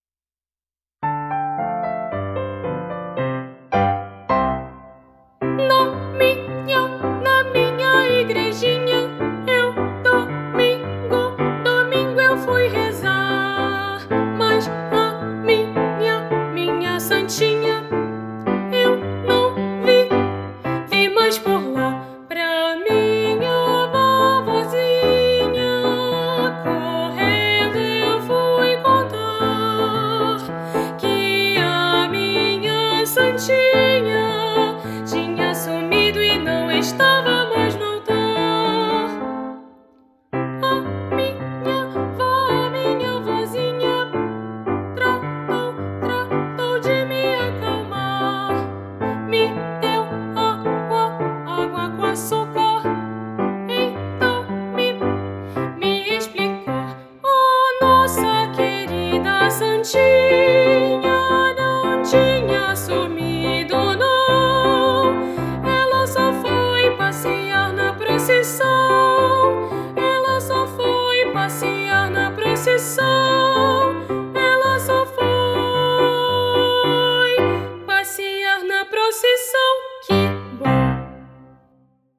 Voz Guia 1